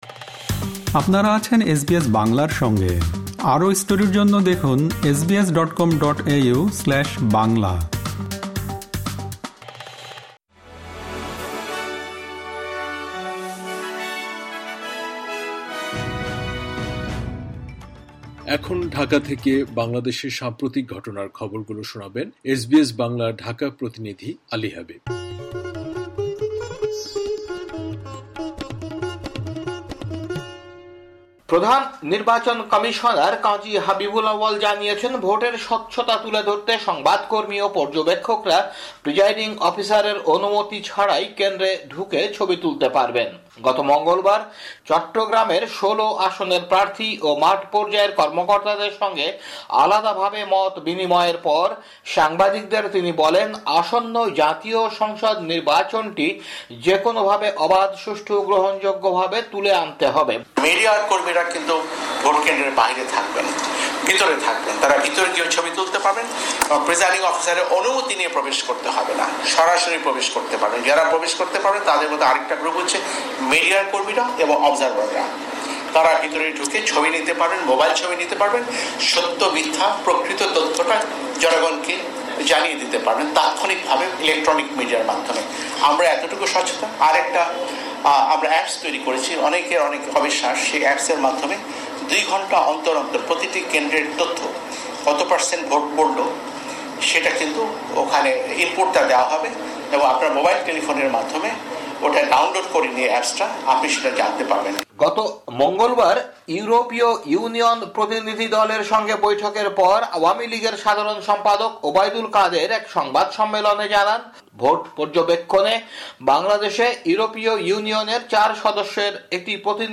বাংলাদেশের সাম্প্রতিক খবর, ২৮ ডিসেম্বর, ২০২৩